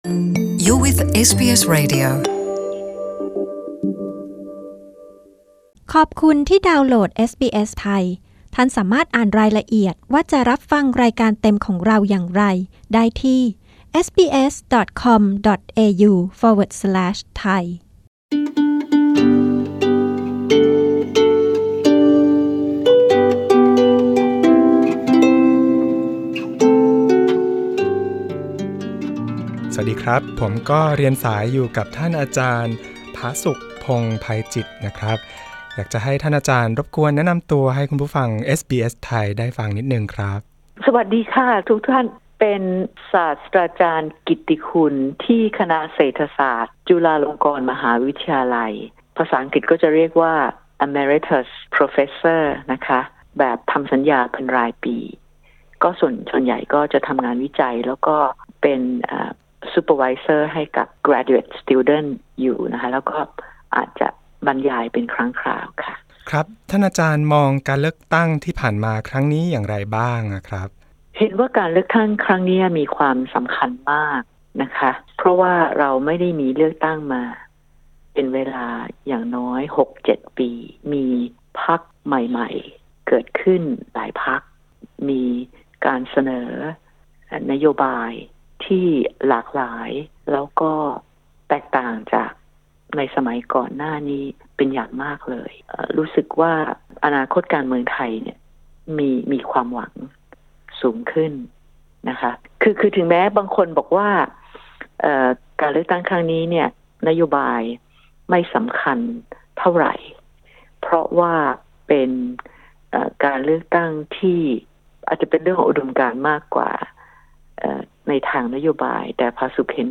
ต้องตีความกันใหม่และผู้นำพรรคอนาคตใหม่ถูกออกหมายจับ เสนอทางออกที่คนไทยทุกคนต้องช่วยกัน กดปุ่ม (▶) ด้านบนเพื่อฟังสัมภาษณ์ศาสตราจารย์ผาสุก เรื่องราวที่เกี่ยวข้อง กกต.สั่งเลือกตั้งใหม่และนับคะแนนใหม่บางเขต การเมืองไทย: สิ้นหวังหรือมีหวัง?